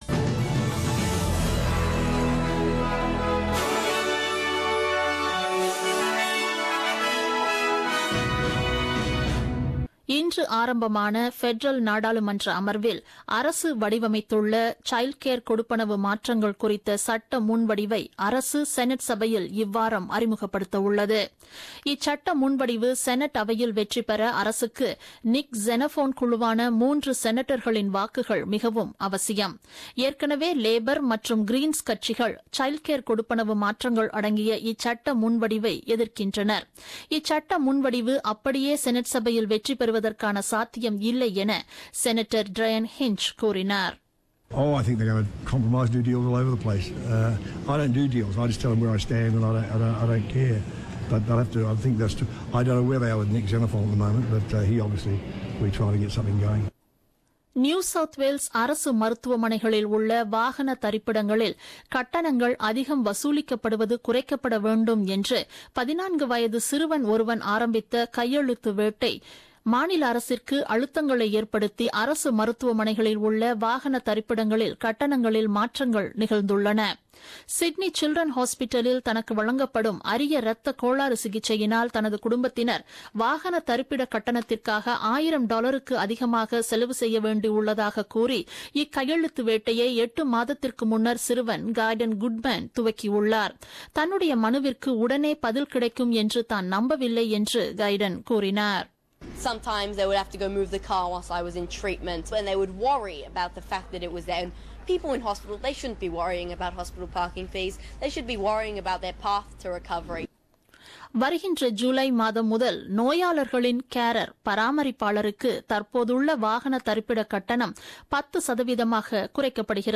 The news bulletin broadcasted on 20th March 2017 at 8pm.